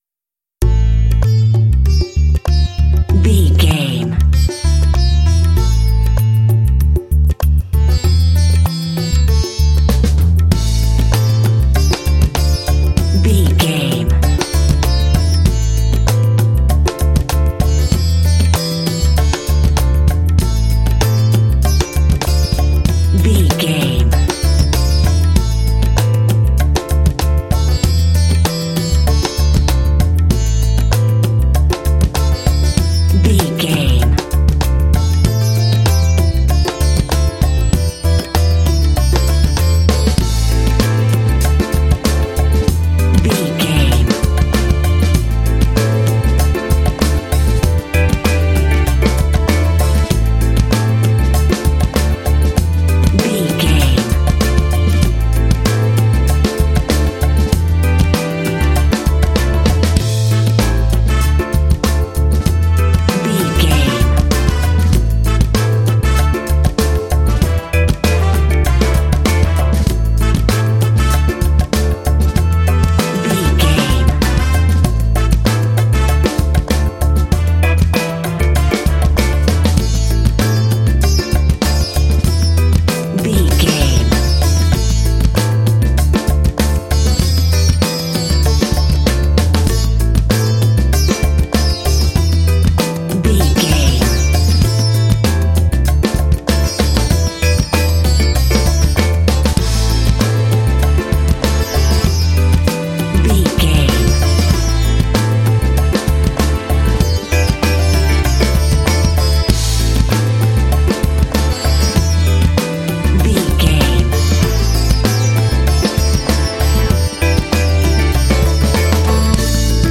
Uplifting
Aeolian/Minor
funky
groovy
smooth
bass guitar
percussion
drums
piano
strings
brass
Funk
downtempo